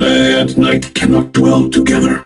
robo_bo_kill_04.ogg